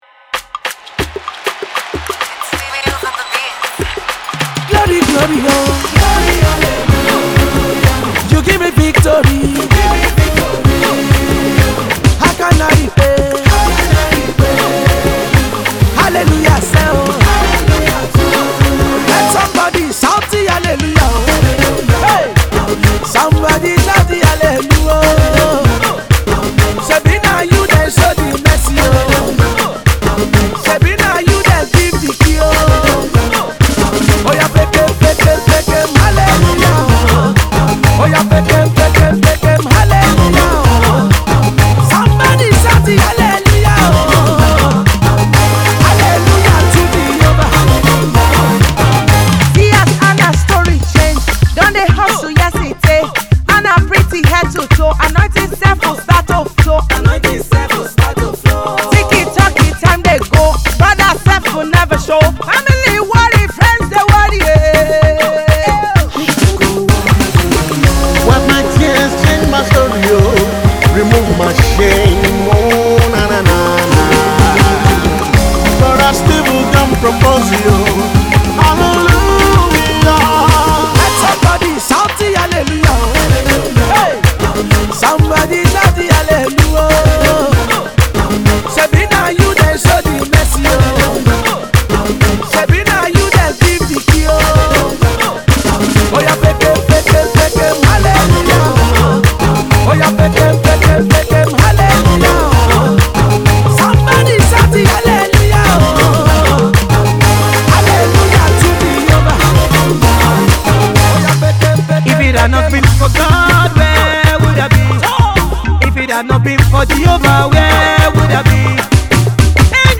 Gospel music quartet